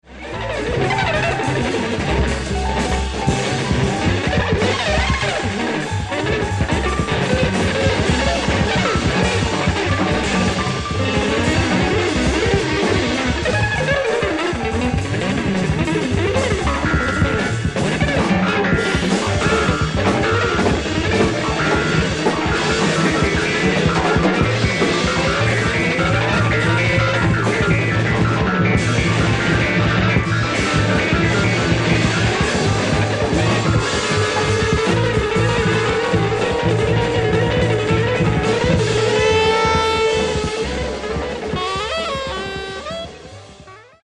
フリー/レアグルーヴ/フレンチ・ジャズ